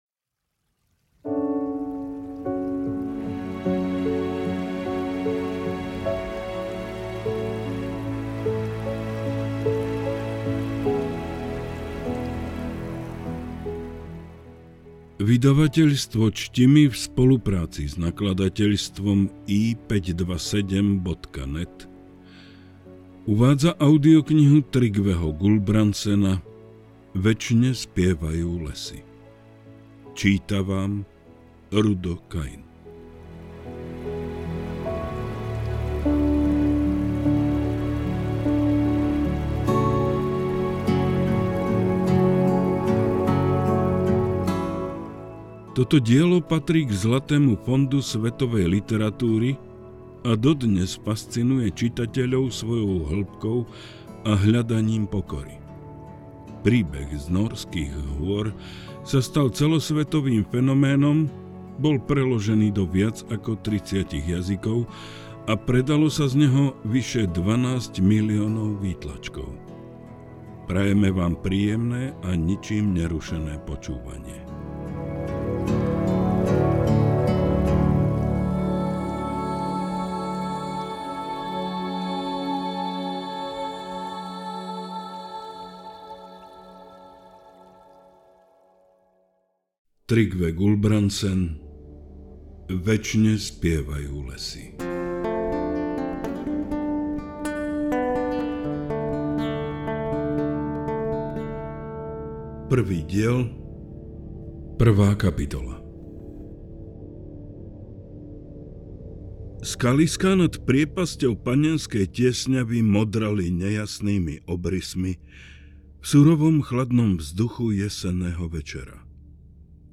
AudioKniha ke stažení, 32 x mp3, délka 12 hod. 45 min., velikost 722,5 MB, slovensky, česky